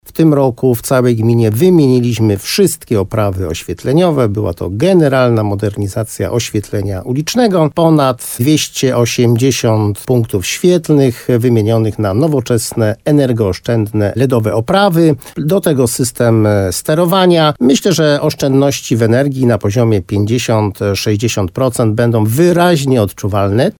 W programie Słowo za Słowo na antenie RDN Nowy Sącz wójt gminy Moszczenica Jerzy Wałęga mówił, że to już ostatni element większego projektu.